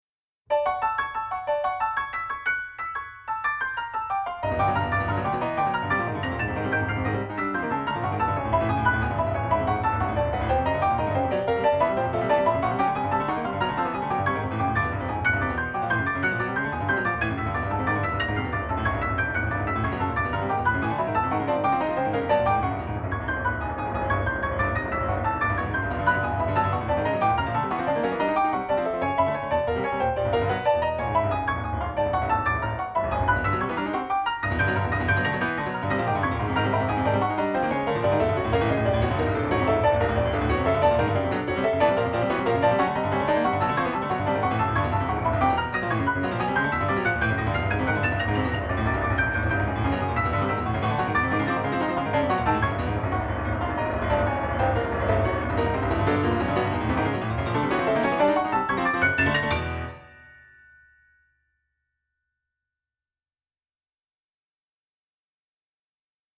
Yamaha Disklavier